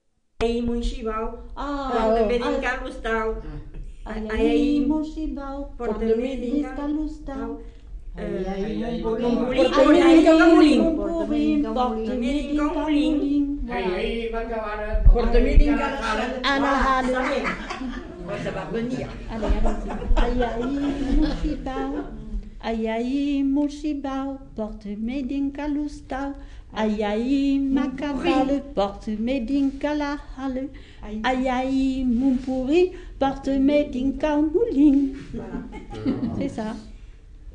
enquêtes sonores
Devinettes